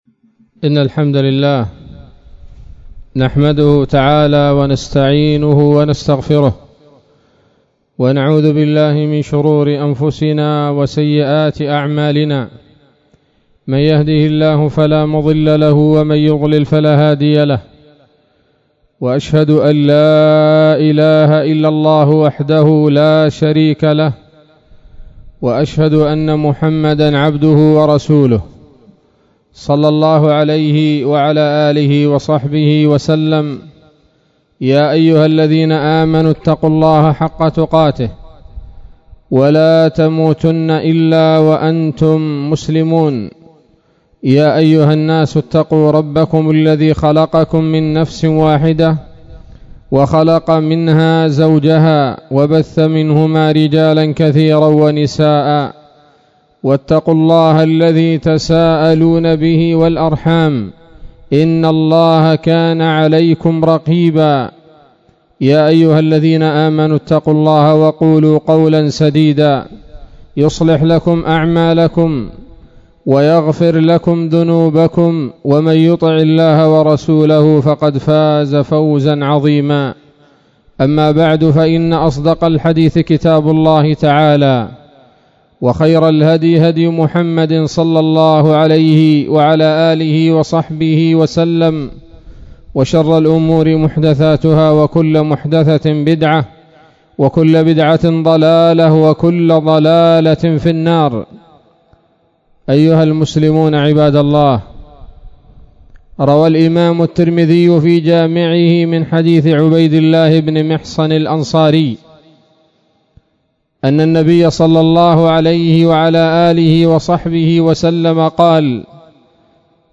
خطبة جمعة بعنوان: (( العافية والصحة تاج على رؤوس الأصحاء لا يراها إلا المرضى